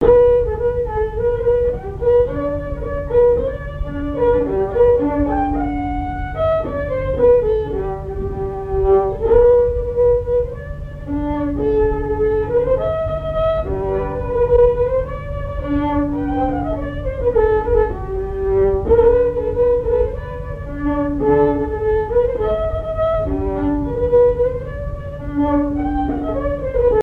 Genre brève
Pièce musicale inédite